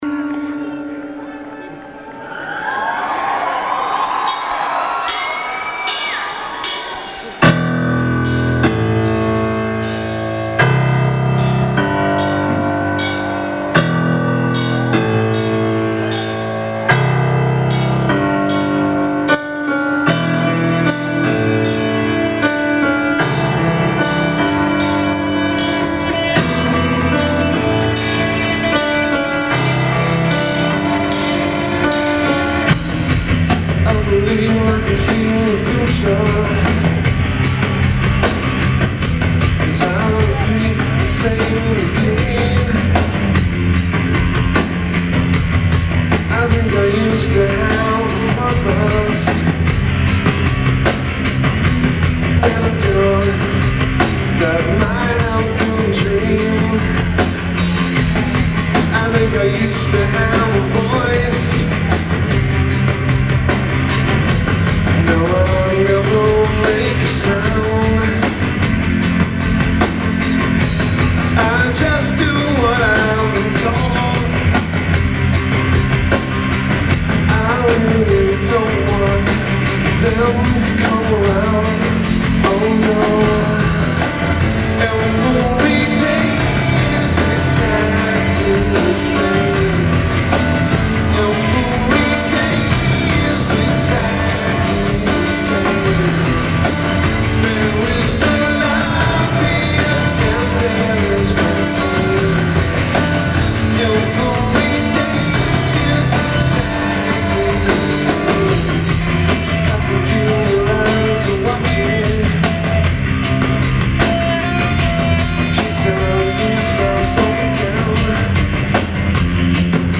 Assembly Hall
Notes: Good recording.